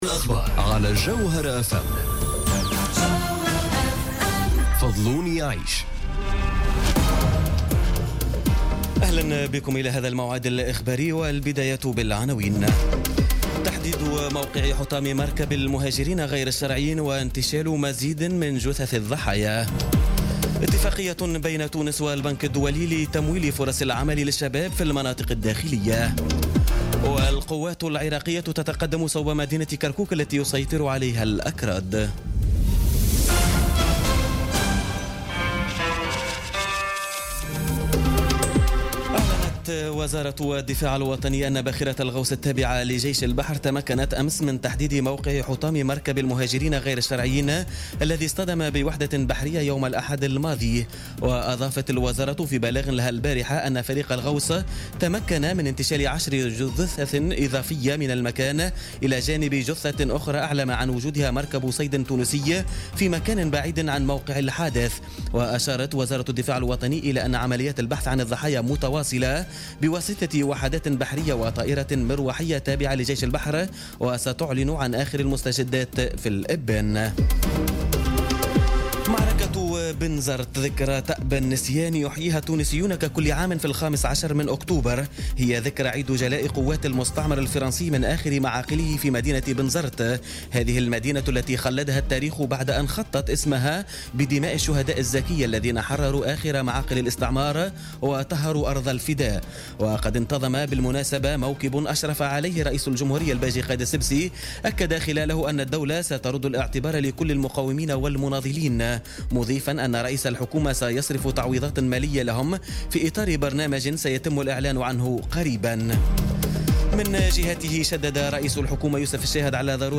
نشرة أخبار منتصف الليل ليوم الإثنين 16 أكتوبر 2017